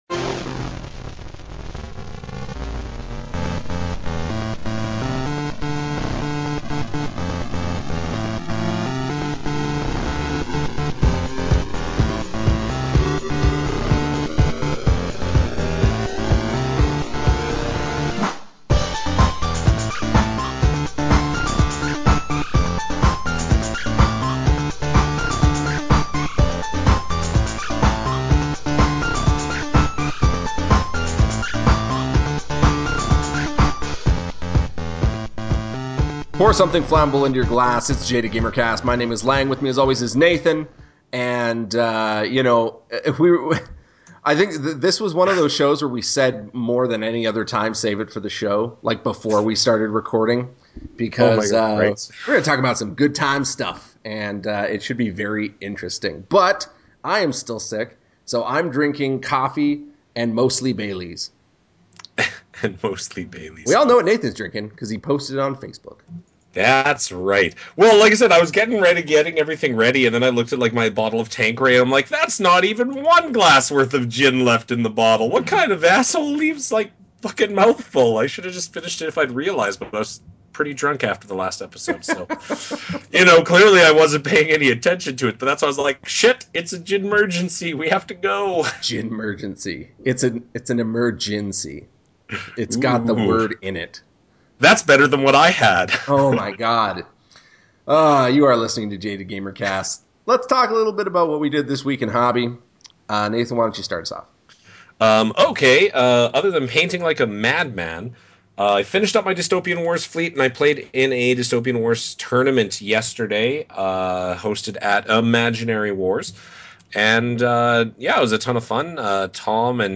How does the new Aquan models stack up against the existing models? Also, movies chat regarding 300: Rise of an Empire as well as drunken ramblings about TV shows.